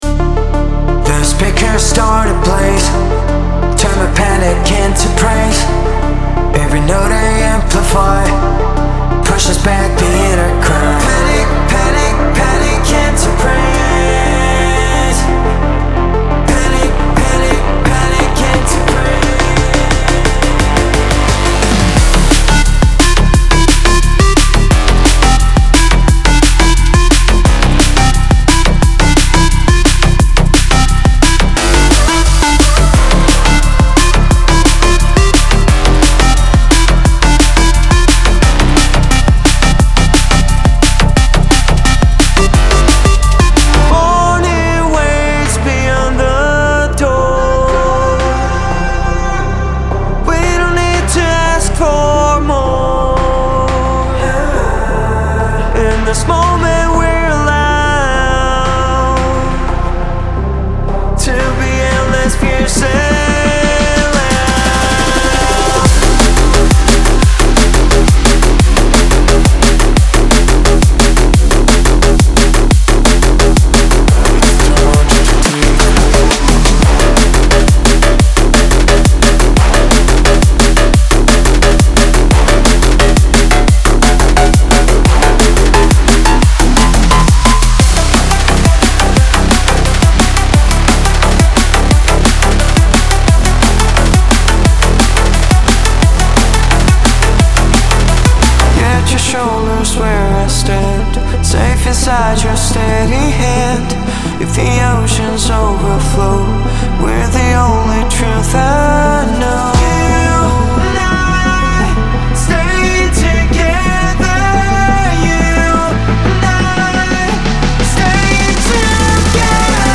Genre:Drum and Bass
デモサウンドはコチラ↓
150, 175 BPM